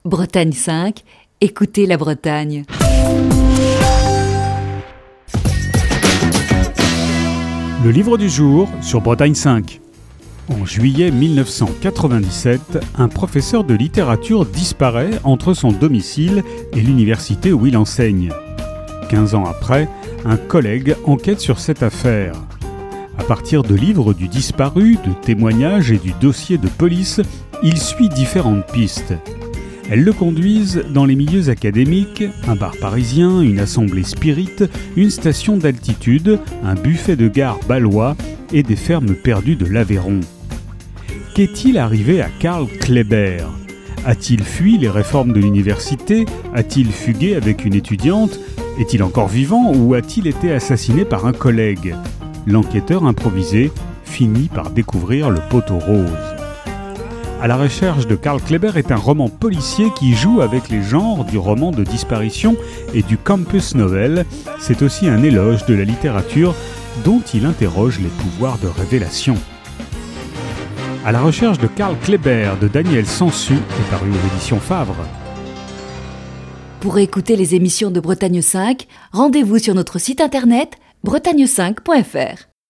Chronique du 13 août 2020.